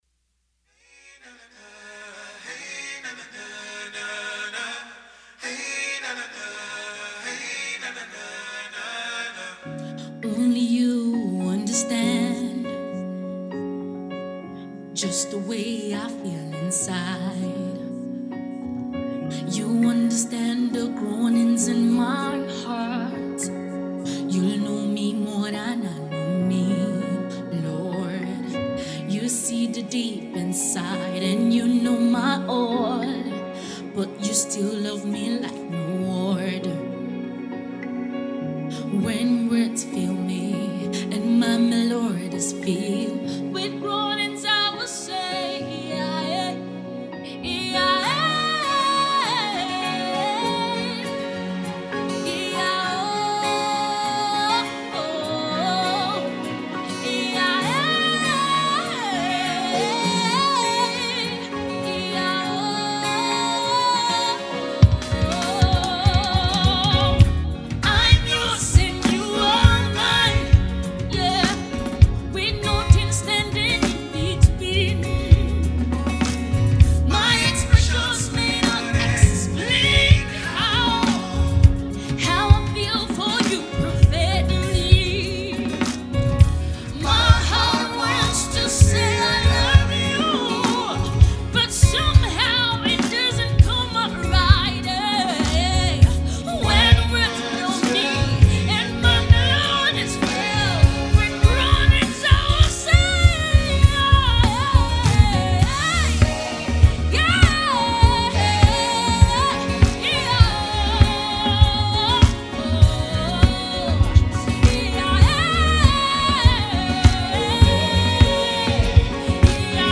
Nigeria gospel music minister and songwriter